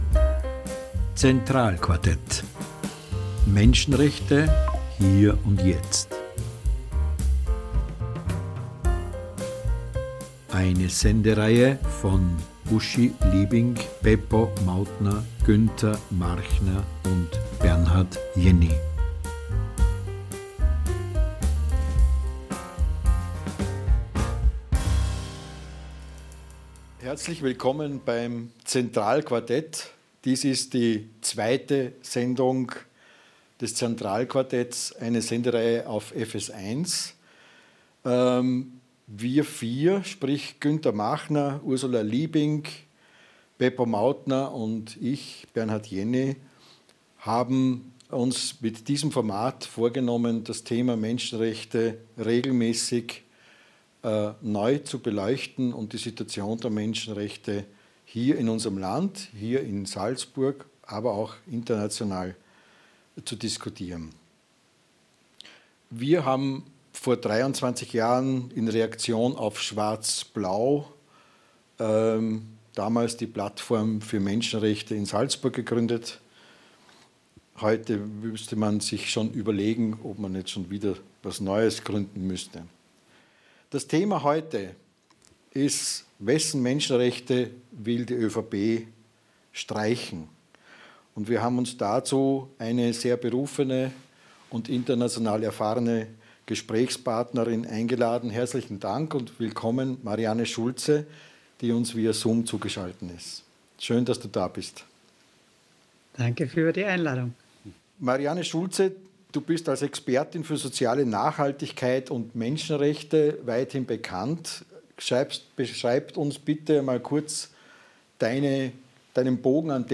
(Aufzeichnung vom 22.11.2022) Eine Produktion bei FS1 – Freies Fernsehen S...
(Aufzeichnung vom 22.11.2022) Eine Produktion bei FS1 – Freies Fernsehen Salzburg.